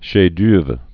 (shā-dœvrə, -dûrv)